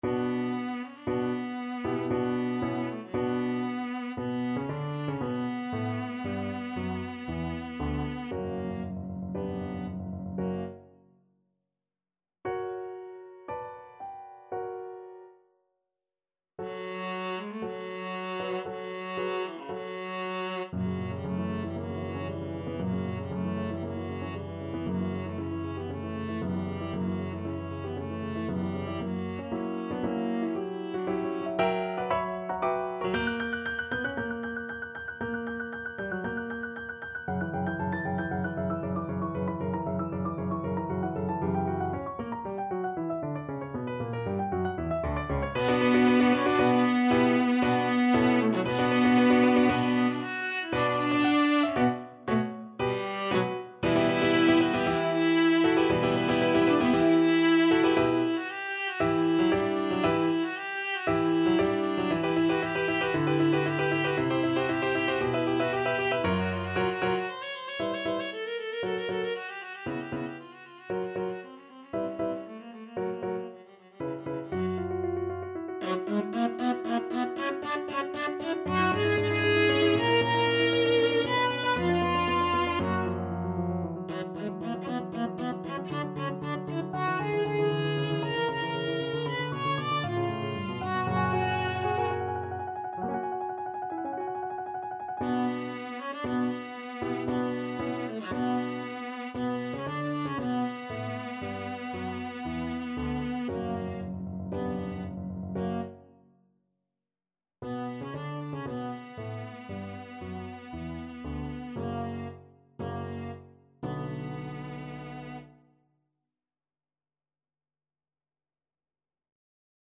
Viola
4/4 (View more 4/4 Music)
B minor (Sounding Pitch) (View more B minor Music for Viola )
Allegro =116 (View more music marked Allegro)
Classical (View more Classical Viola Music)
dvorak_cello_concerto_1st_main_VLA.mp3